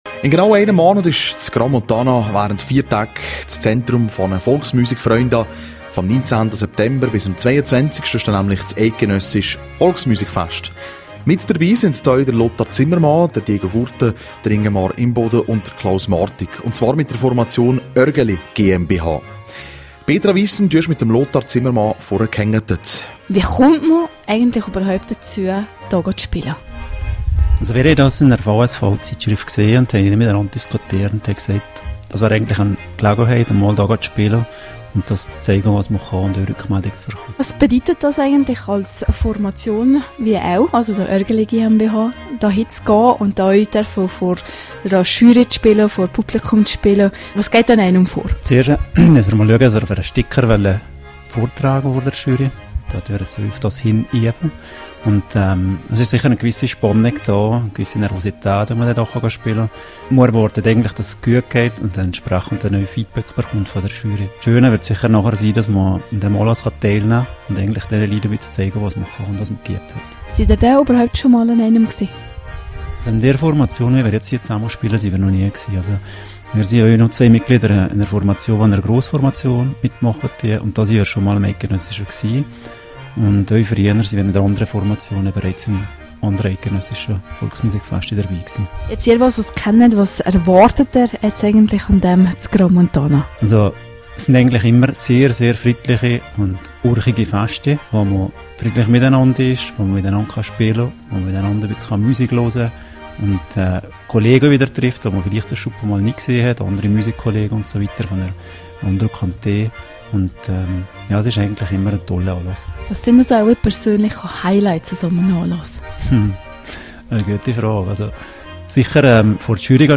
Die Formation Örgeli GmbH schleift an einer Eigenkomposition für das diesjährige eidgenössische Volksmusikfest in Crans-Montana.